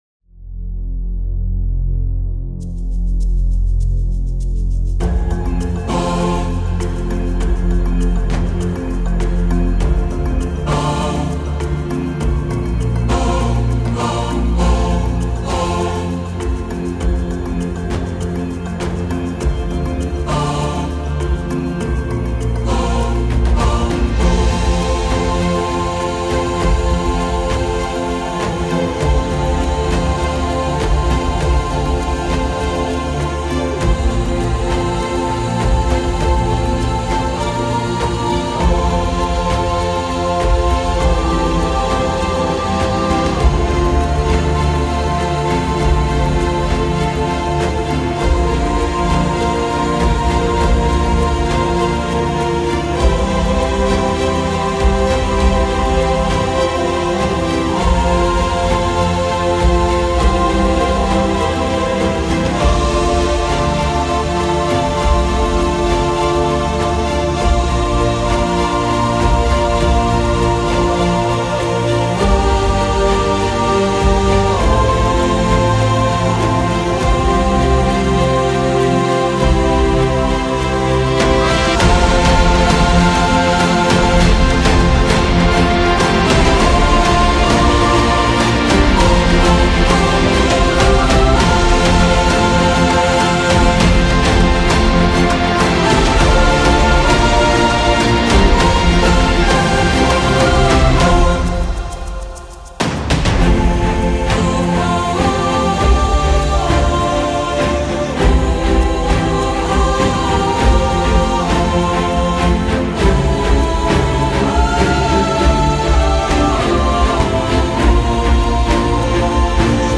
Epic Choir
Sopranos
Altos
Tenors
Basses